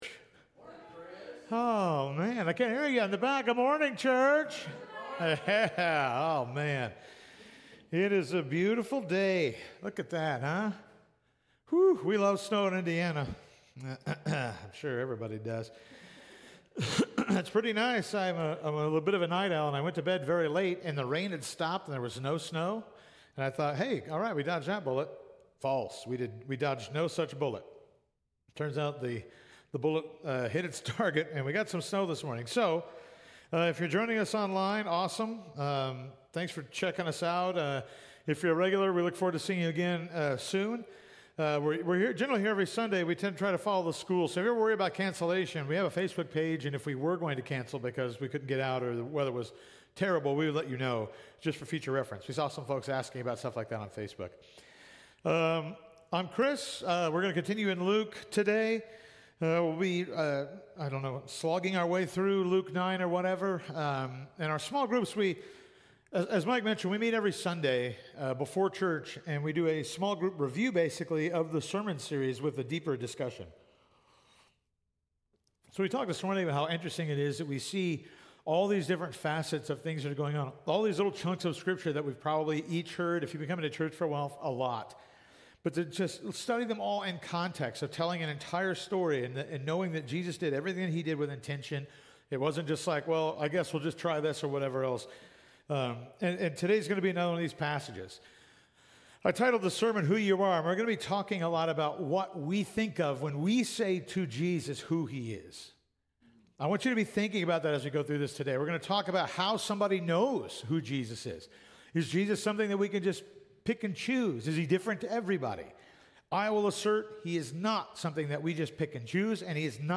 Sermons by Calvary Heights Baptist Church